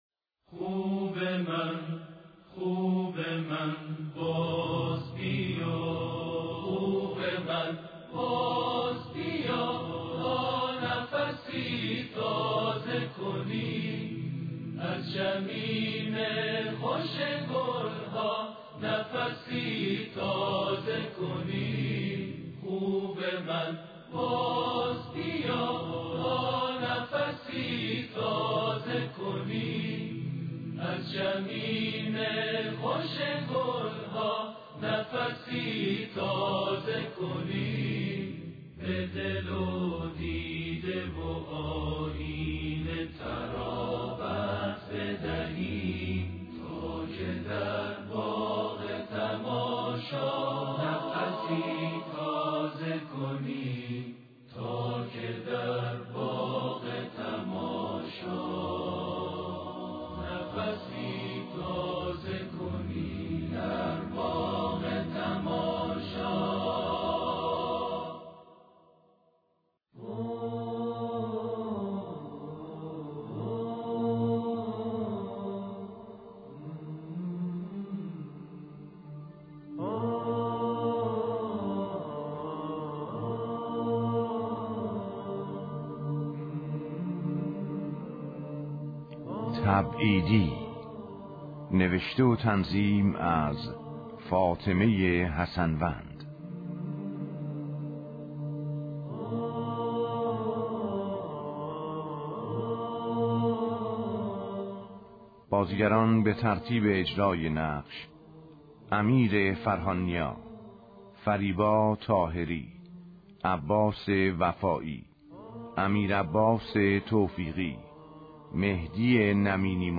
نمایش رادیویی - آیت الله سید اسد الله مدنی